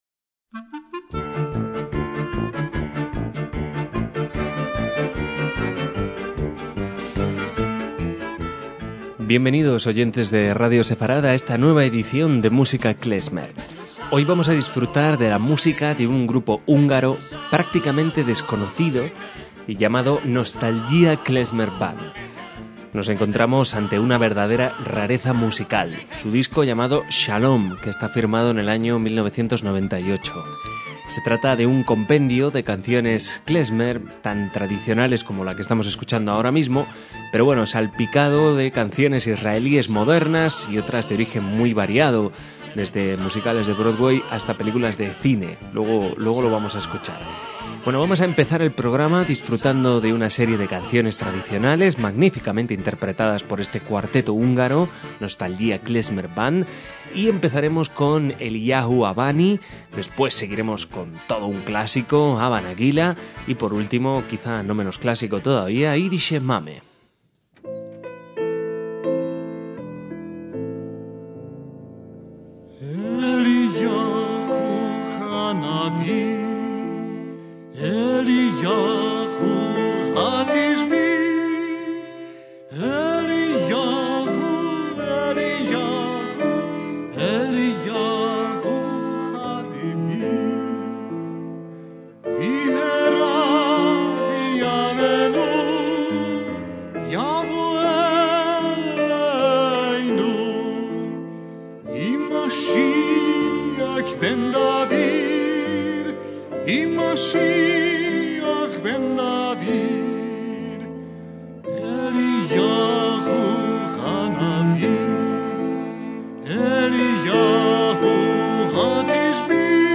MÚSICA KLEZMER
chelo
acordeón
clarinete
batería
violín
piano
tuba